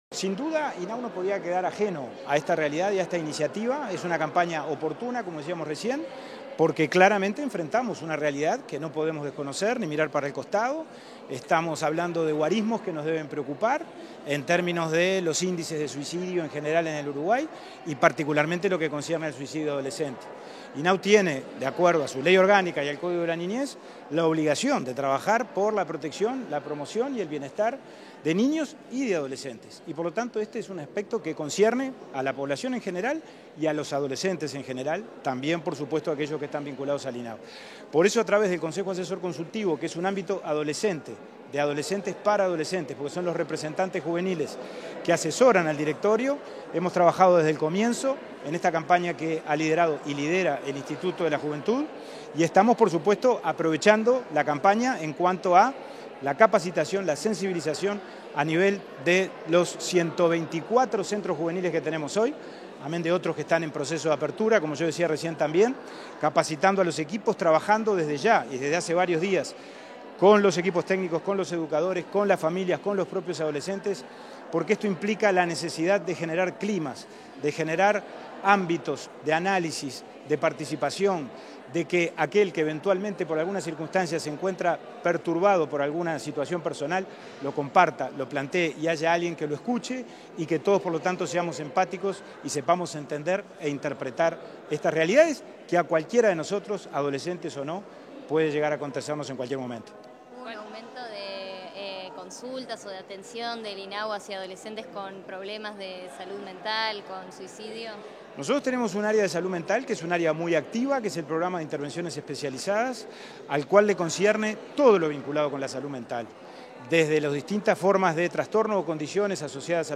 Declaraciones a la prensa del presidente del INAU, Pablo Abdala